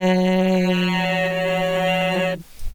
These examples were all generated using the FAST-Auto pitch detector with threshold around 0.05, a 4000 point window, and a 1000 point jump size, using the PSOLA correction algorithm.
The corrector helps to pick him up and keep him on the right note.